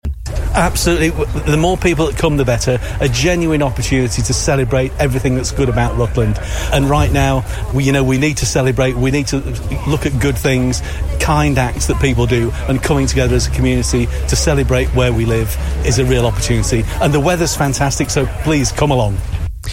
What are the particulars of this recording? Hundreds celebrate Rutland in the county service at Peterborough Cathedral.